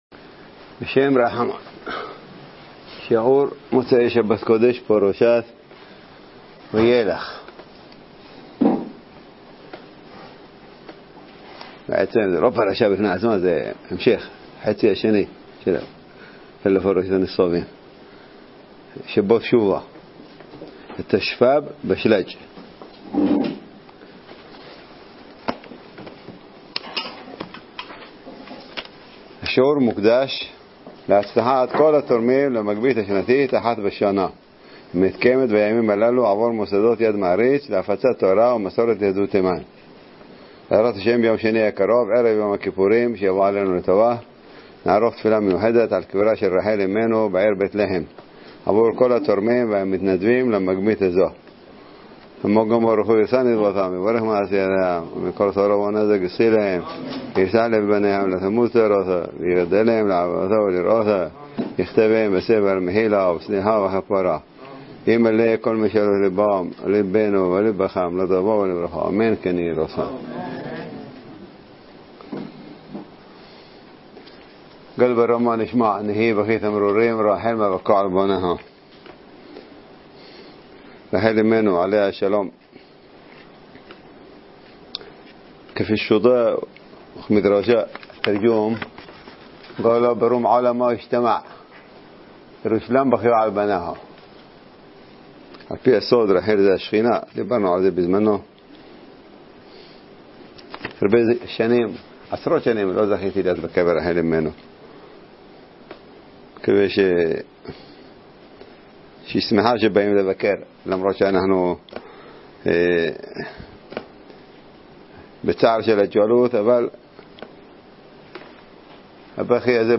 מוצש"ק וילך - דרשת שבת שובה התשפ"ג